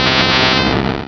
Cri de Tentacruel dans Pokémon Rubis et Saphir.